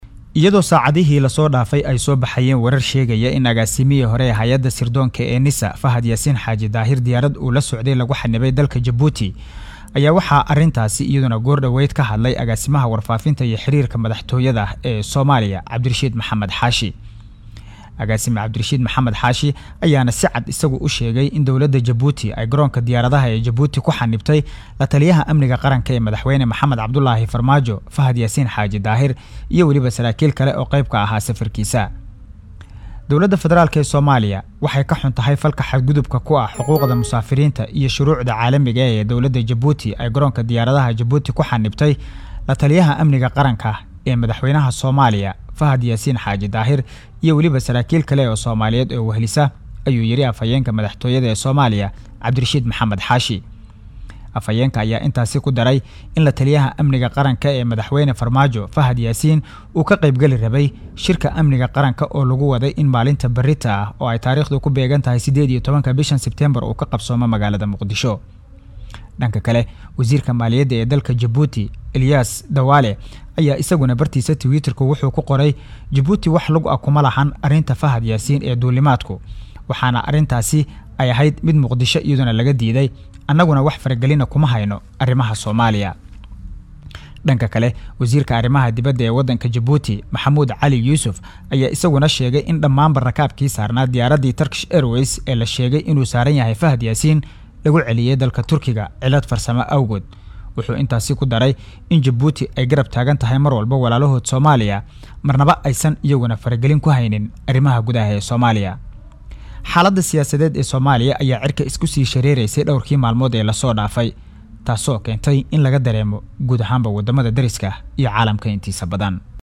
Wararka Soomaaliya